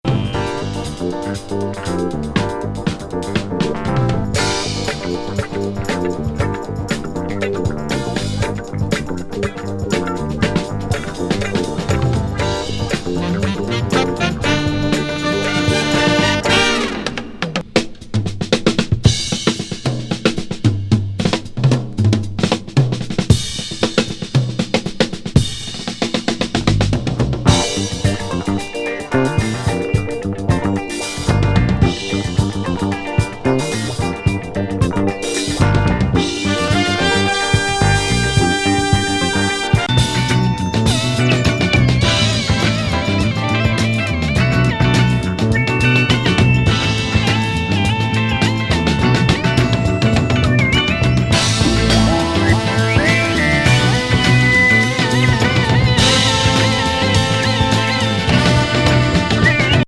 強力ワウワウ和レア・グルーブ
ドラム・ソロからのファンキー
コズミック・シンセ入り